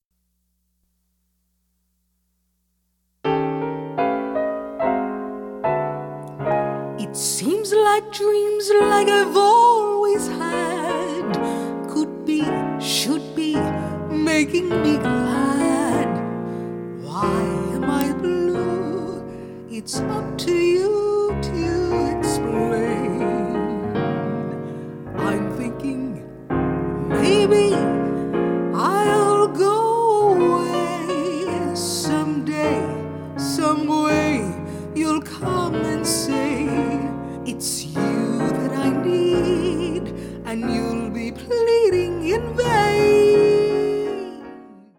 Pno/Vocal
Female Voice